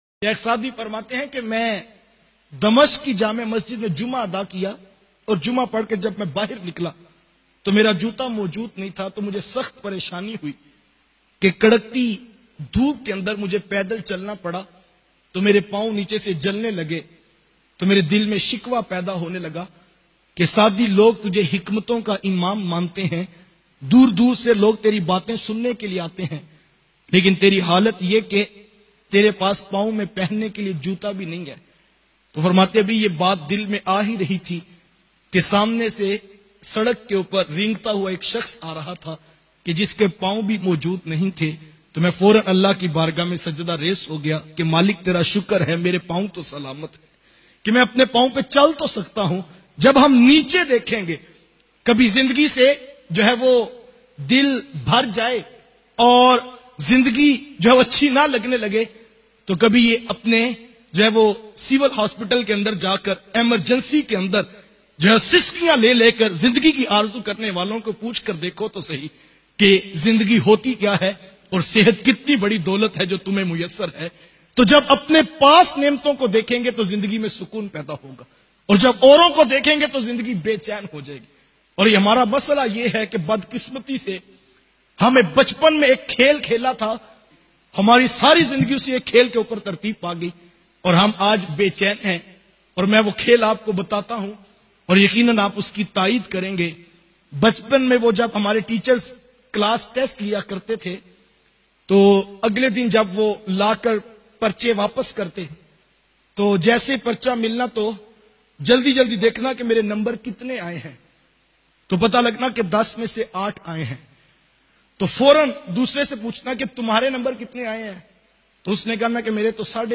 Bachpan Ka Khel Zindagi Bhar Saath Raha bayan mp3